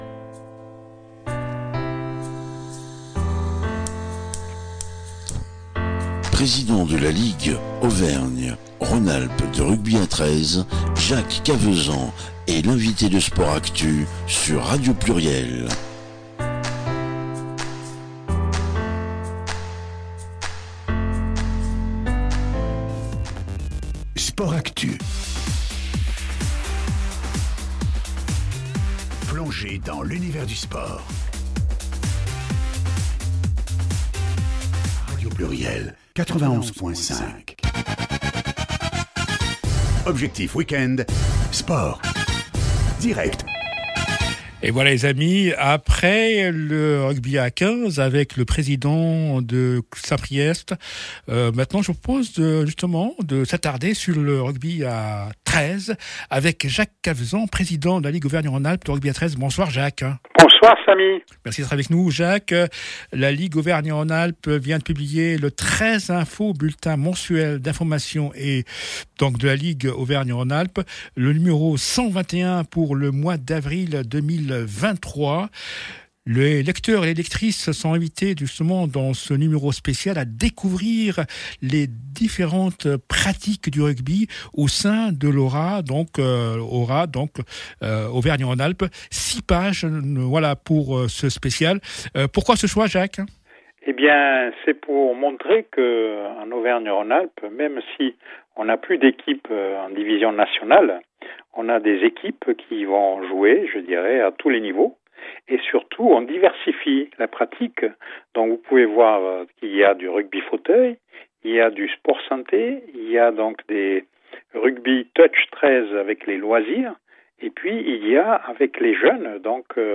L’interview du président mois de MARS 2026